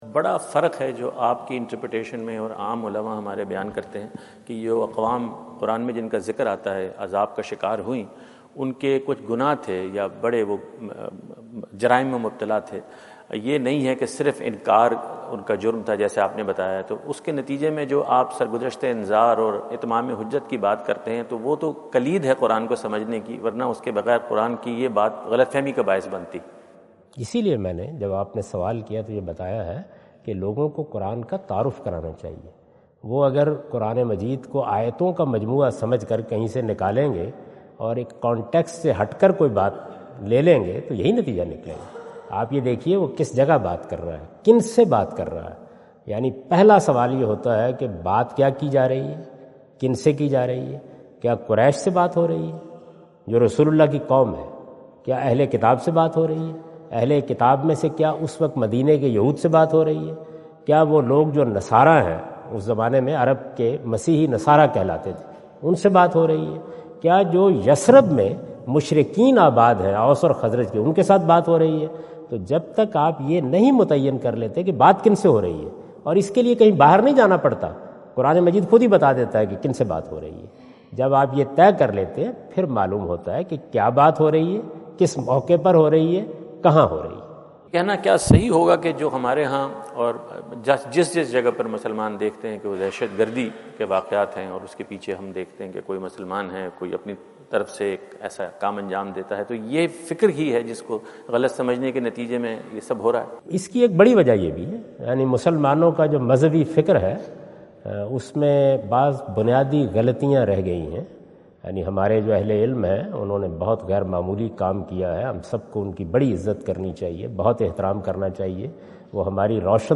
Javed Ahmad Ghamidi answer the question about "Incidents of terrorism, Quran’s teachings and Muslim’s responsibility?" During his US visit at Wentz Concert Hall, Chicago on September 23,2017.
جاوید احمد غامدی اپنے دورہ امریکہ2017 کے دوران شکاگو میں "دہشت گردی کے واقعات، قرآن کی تعلیم اور مسلمانوں کی ذمہ داری؟" سے متعلق ایک سوال کا جواب دے رہے ہیں۔